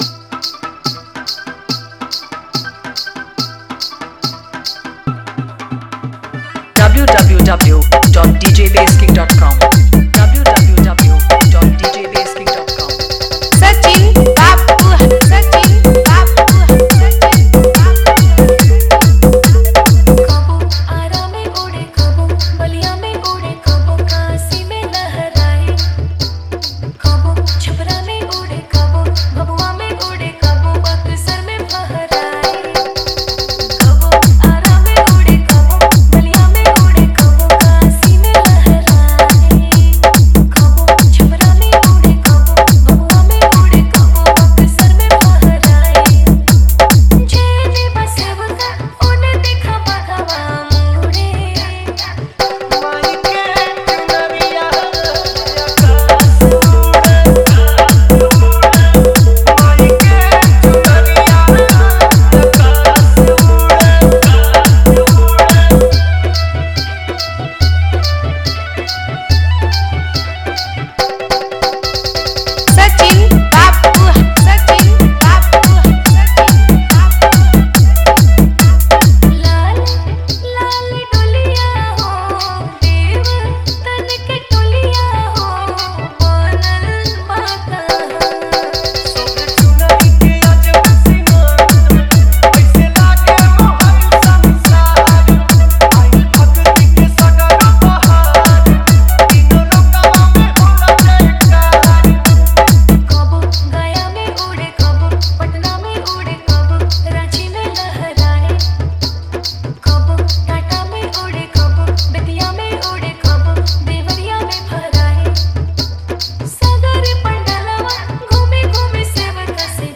Navratri Dj Remix Songs